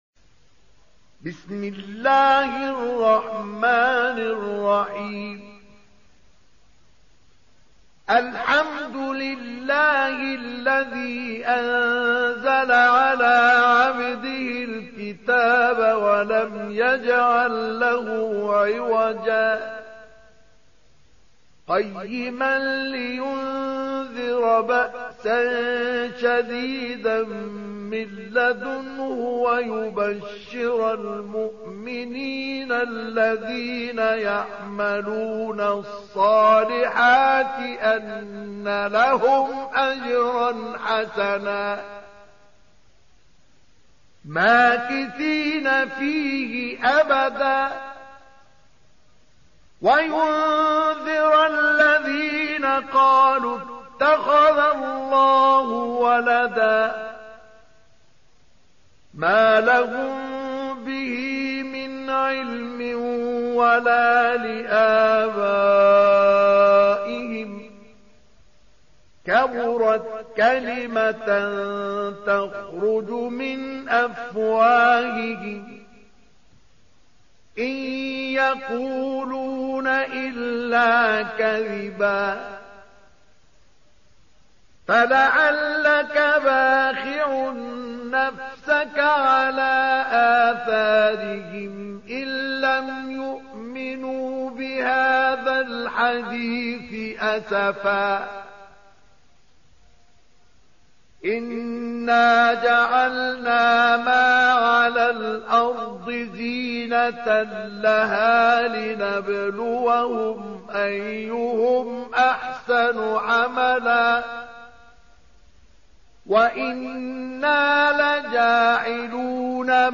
Audio Quran Tarteel Recitation
Surah Sequence تتابع السورة Download Surah حمّل السورة Reciting Murattalah Audio for 18. Surah Al-Kahf سورة الكهف N.B *Surah Includes Al-Basmalah Reciters Sequents تتابع التلاوات Reciters Repeats تكرار التلاوات